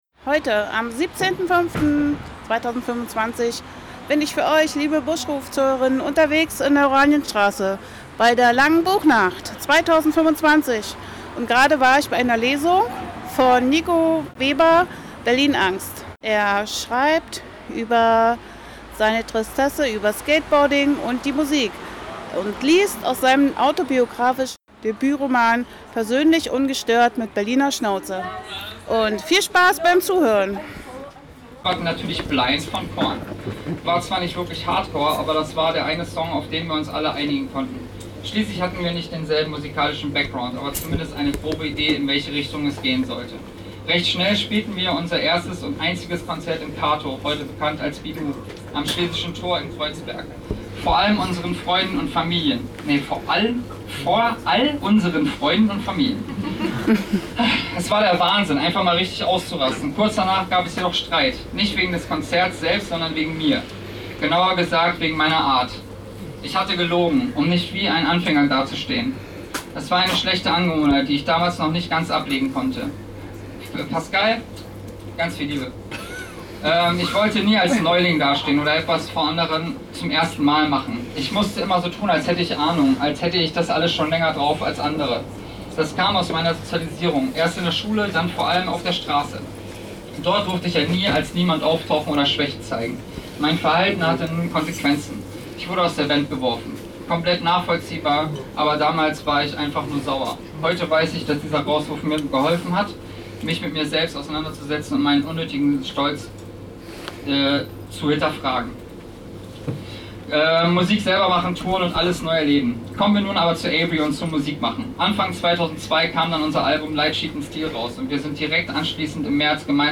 Zwischendurch immer mal beim „Goldenen Hahn“in der Oranienstr. 14a vorbeigeschaut und gelauscht bei den tollen Lesungen.